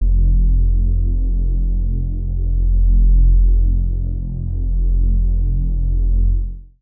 TS Synth Bass_2.wav